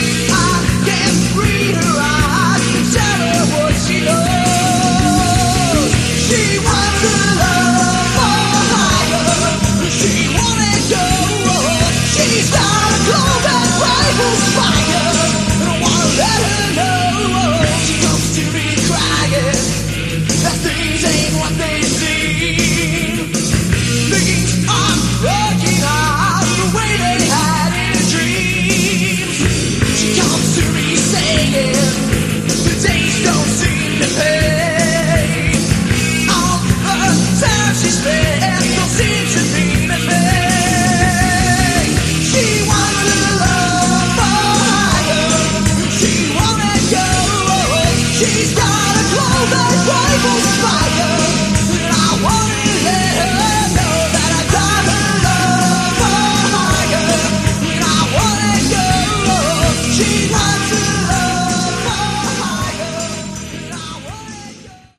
Category: Sleaze Glam
vocals
guitar
bass
drums
original demo